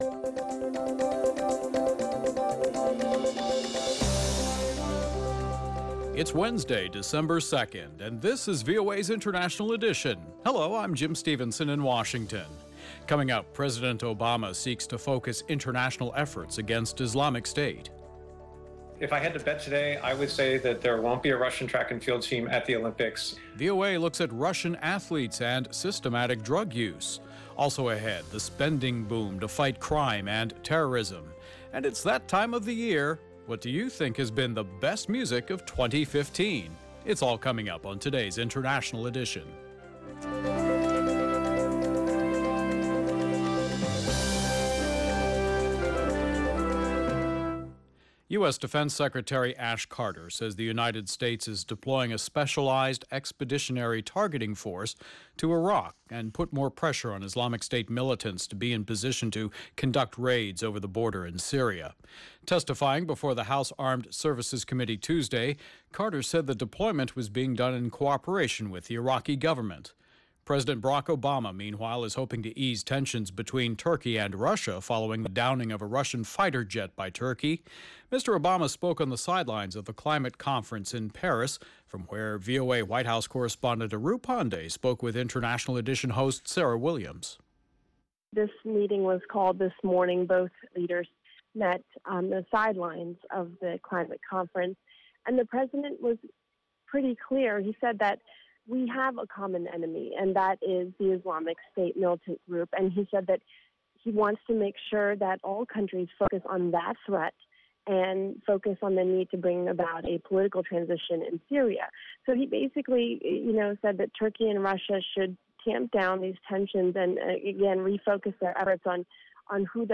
LIVE at 2205 UTC, Replayed at 2305 UTC International Edition delivers insight into world news through eye-witnesses, correspondent reports and analysis from experts and news makers. We also keep you in touch with social media, science and entertainment trends.